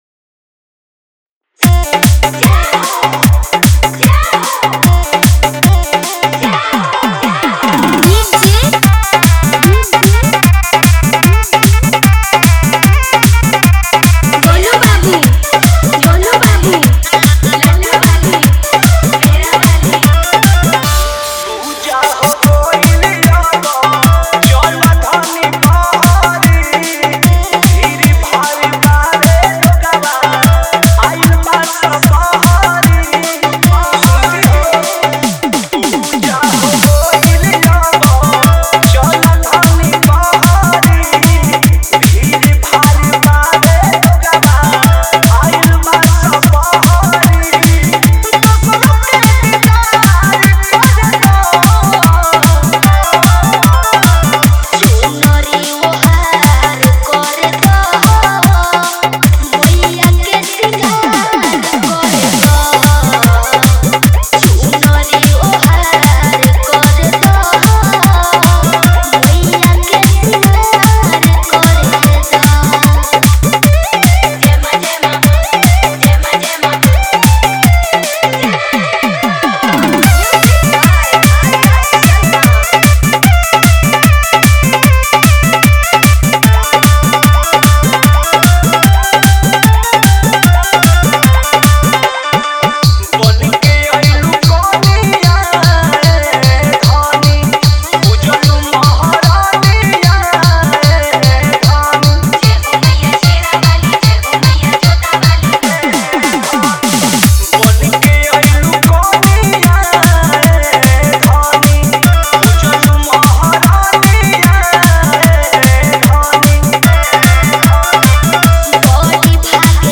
Bhakti Dj Songs